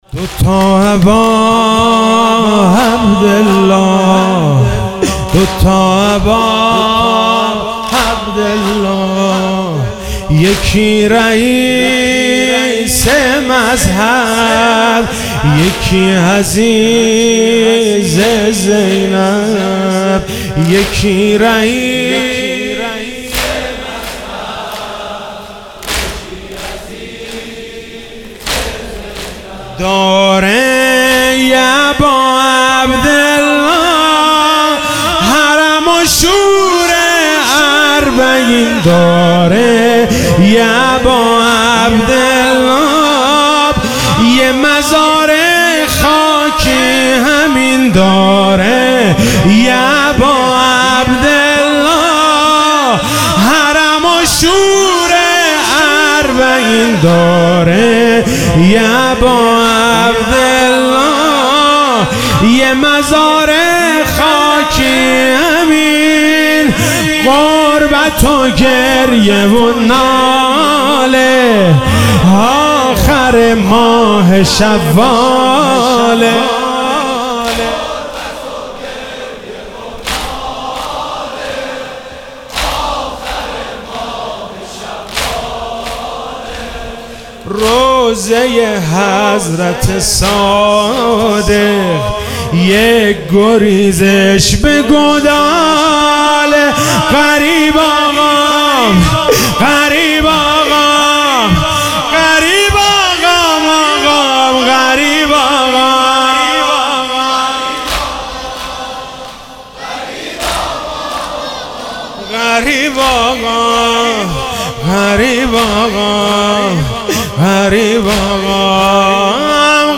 شهادت امام صادق علیه السلام1400